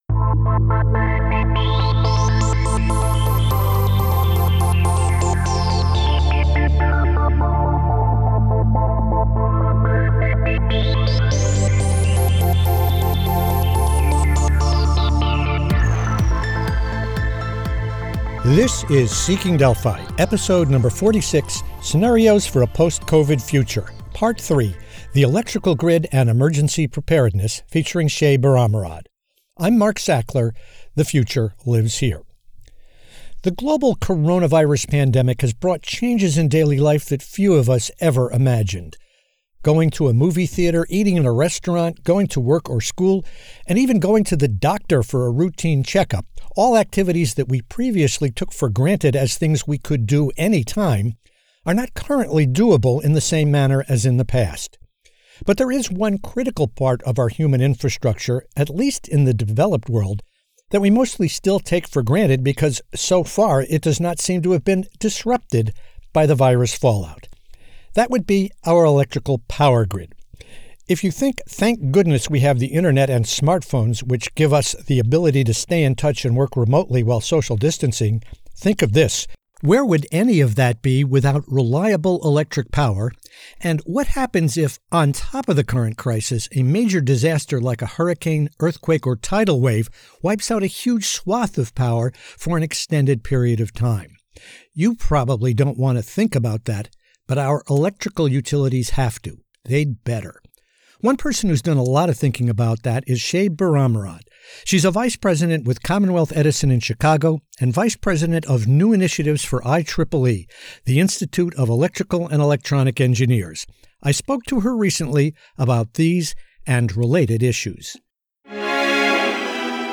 She joins me to discuss just such scenarios and how they are being prepared for by our power providers.